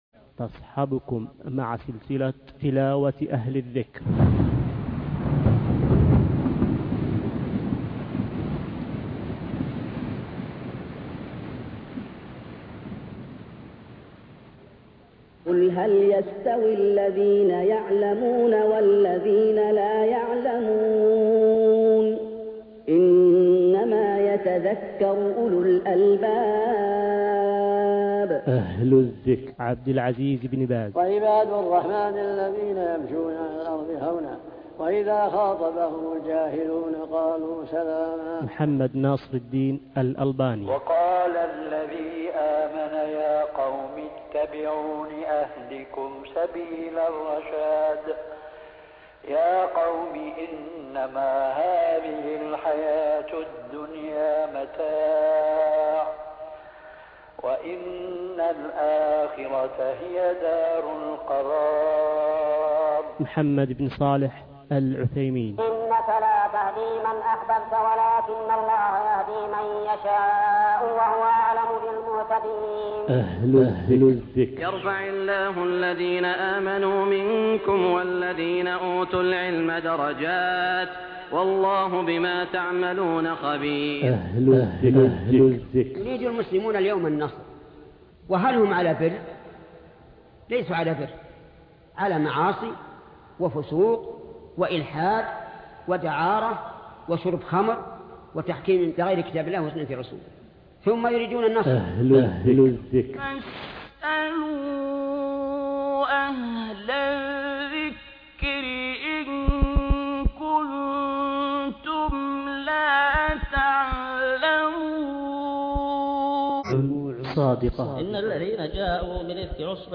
القارئ: فضيلة الشيخ محمد ناصر الدين الألباني الصنف: تلاوات
رواية : حفص عن عاصم